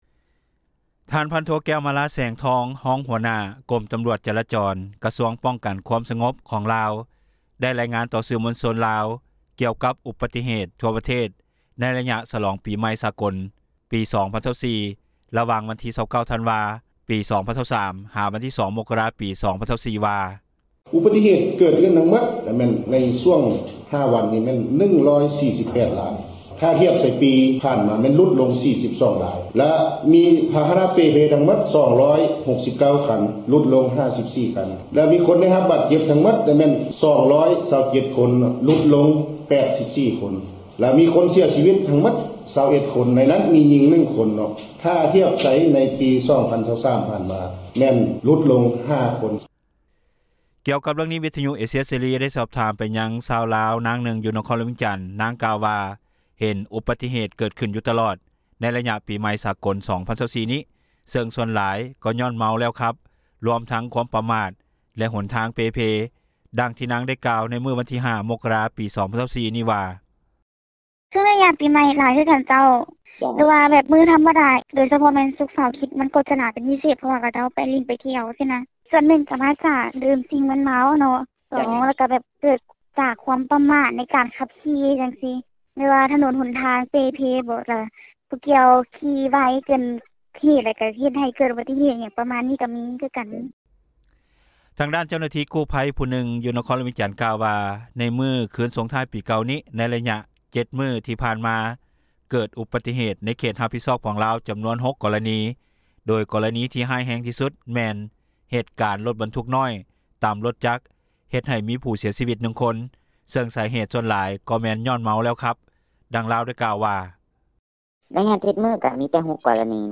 ກ່ຽວກັບເຣື່ອງນີ້, ວິທຍຸເອເຊັຽເສຣີ ໄດ້ສອບຖາມຊາວລາວ ນາງນຶ່ງ ຢູ່ນະຄອນຫຼວງວຽງຈັນ. ນາງກ່າວວ່າ ເຫັນອຸບັດຕິເຫດເກີດຂຶ້ນຢູ່ຕລອດ ໃນໄລຍະປີໃໝ່ສາກົລ 2024 ນີ້ ເຊິ່ງສ່ວນຫຼາຍຍ້ອນເມົາແລ້ວຂັບ ລວມທັງ ຄວາມປະໝາດ ແລະຫົນທາງເປ່ເພ.